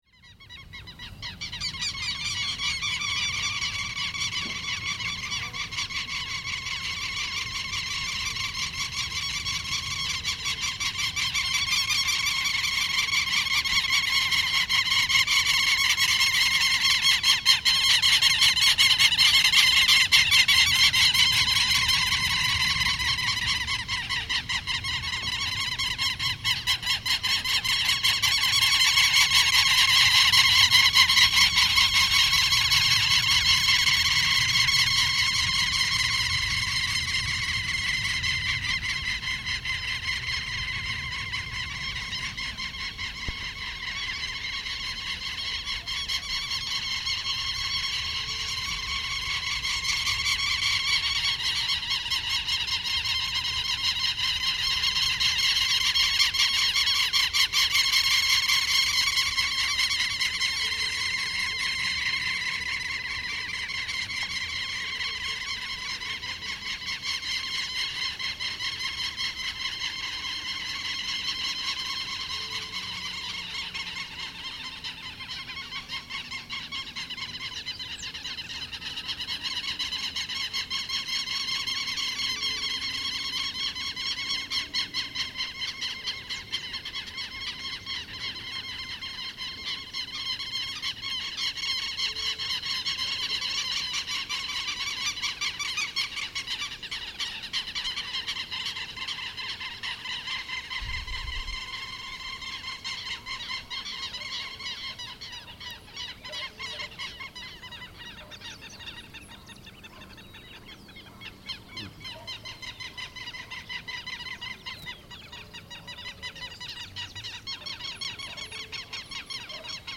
Field recording capture on a rural area in Santuario, Antioquia, Colombia.
The recording was made at 6:30 am, cloudy Sunday's morning and it captures the aerial dance of some local birds.
At the distance some dogs barking from time to time.